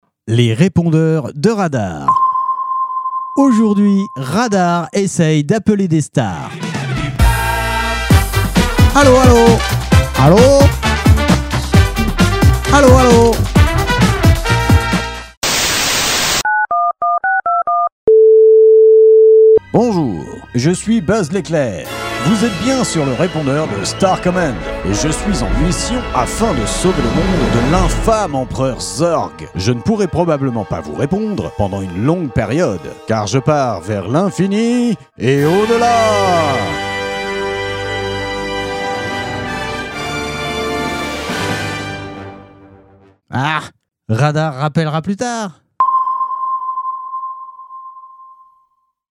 Les répondeurs de Radar parodies répondeurs stars radar